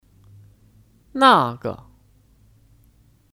那个 Nàge (Kata tunjuk): Yang itu